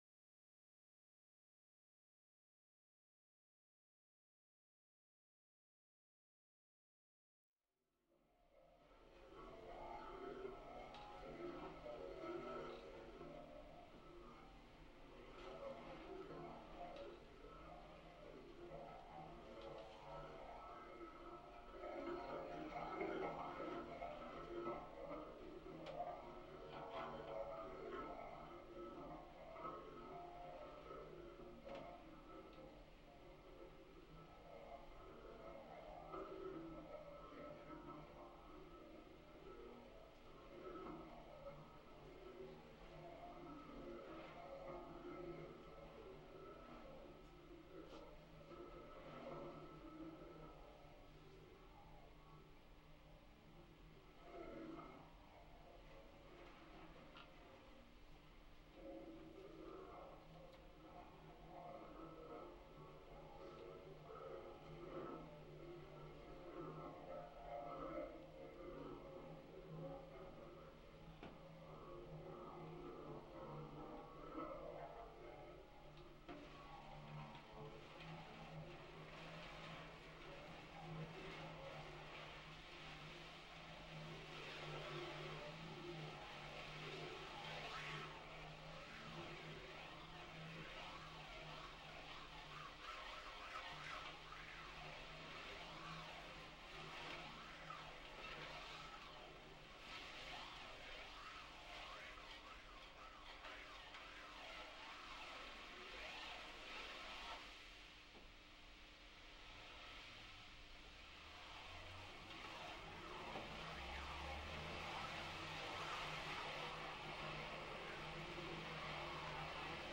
MUSIC ONLY